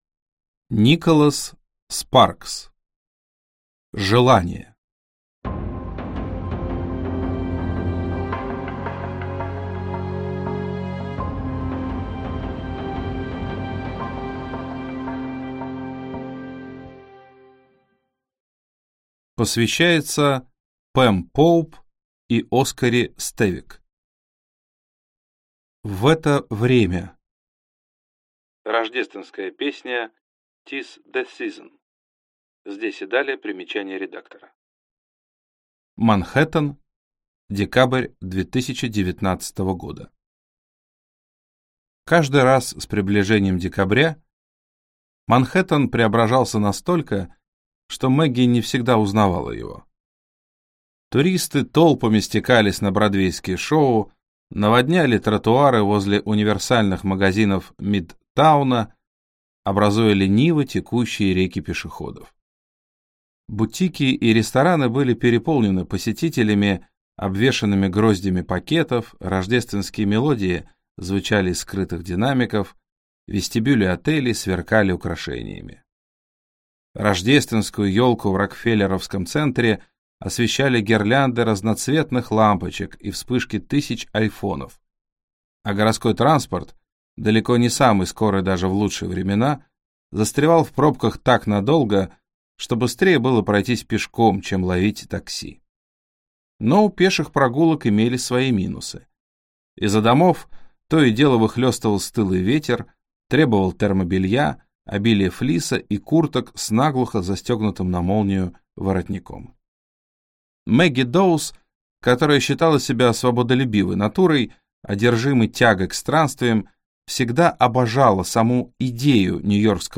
Аудиокнига Желание | Библиотека аудиокниг